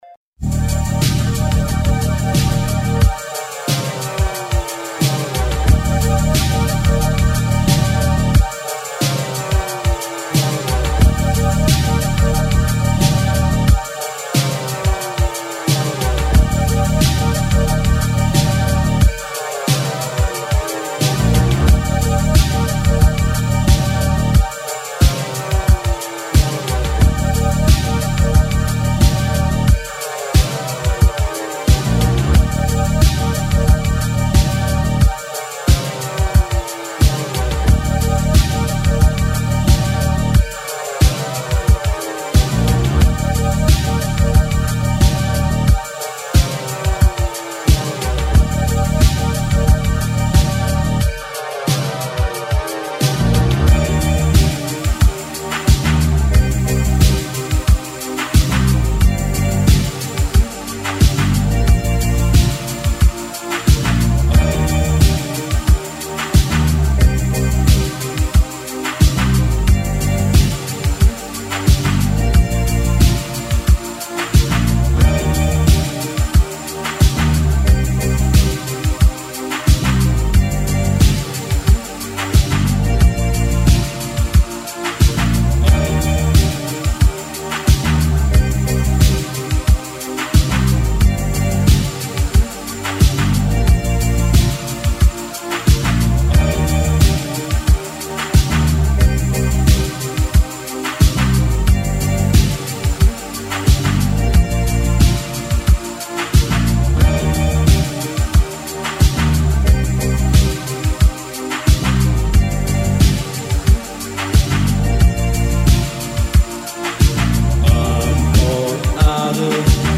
Musica a 360°, viva, legata e slegata dagli accadimenti.